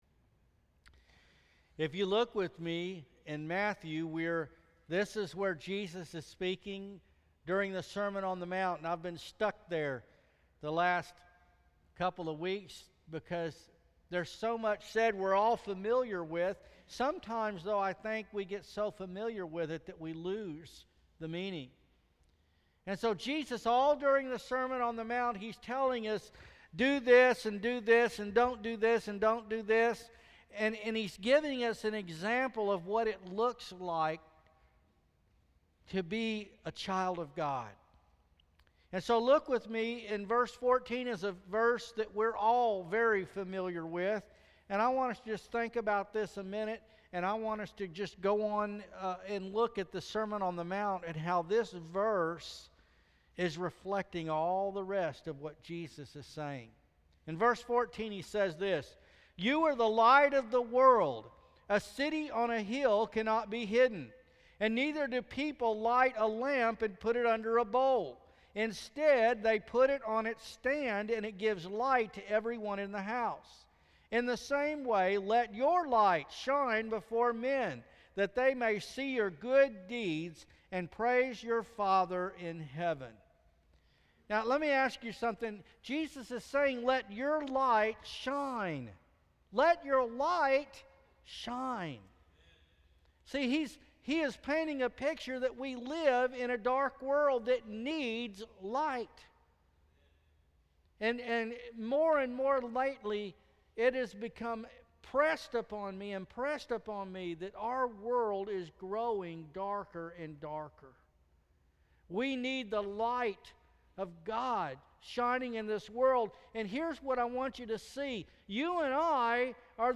July 19, 2015 How to Shine Passage: Matthew 5:14-16; 38-45 Service Type: Sunday Morning Worship Bible Text: Matthew 5:14-16; 38-45 We are the light of the world.